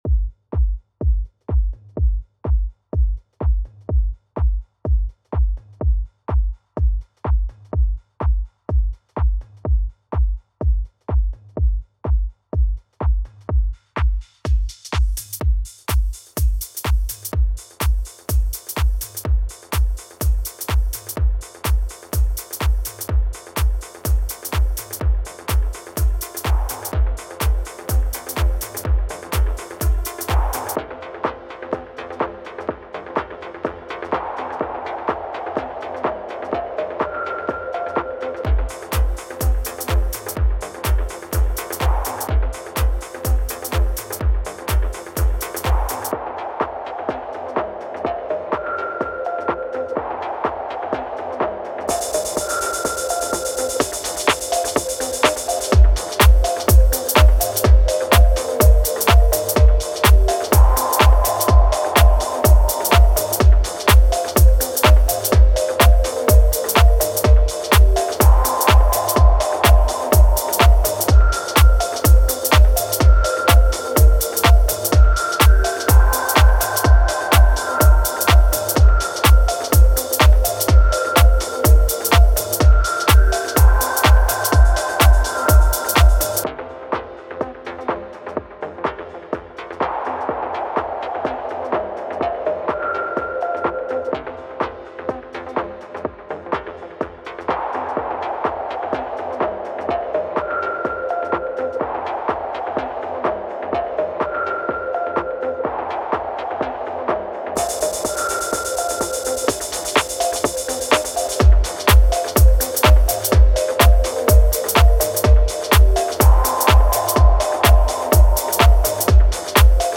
Deep House
Chilled and rhythmic deep house sounds